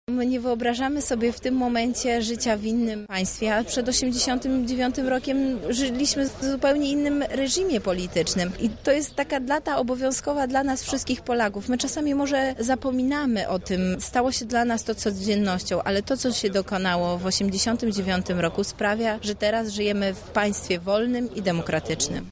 Wszystko było poświęcone tej wyjątkowej rocznicy.– mówi Beata Stepaniuk-Kuśmierzak zastępca prezydenta miasta Lublin